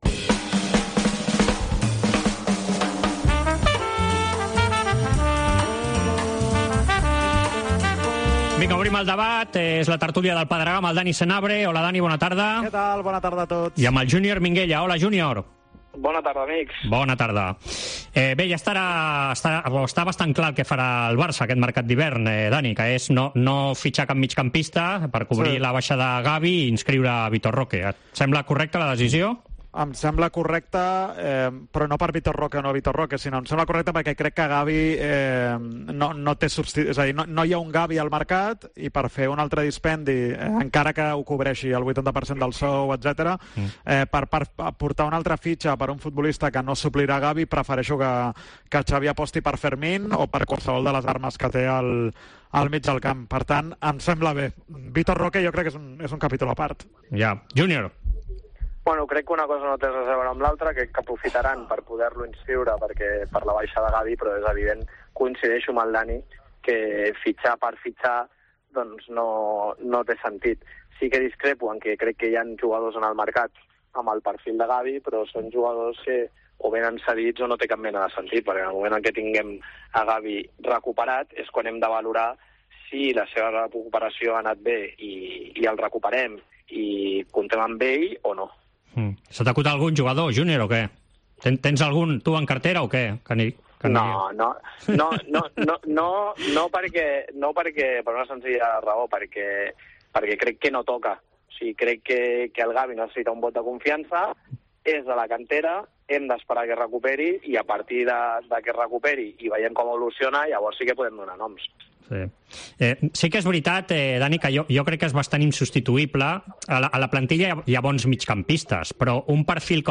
AUDIO: Els dos col·laboradors de la Cadena COPE repassen l'actualitat esportiva de la setmana.